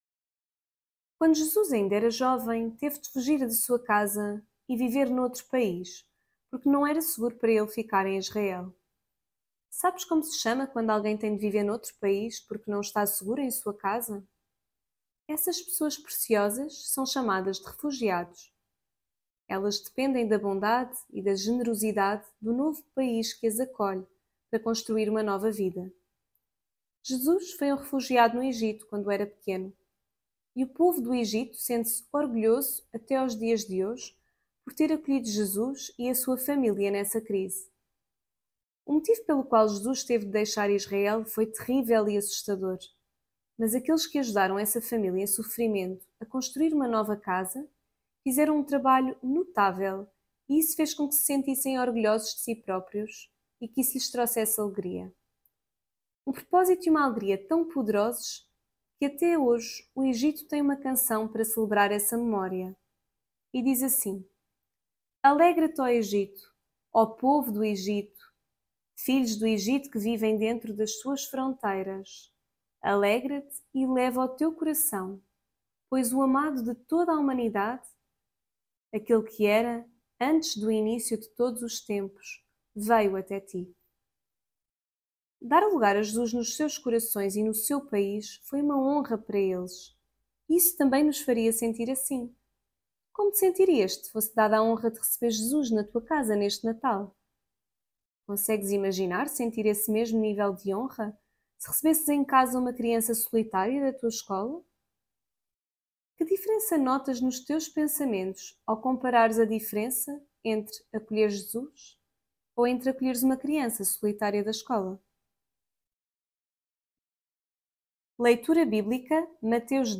Devocional Coríntios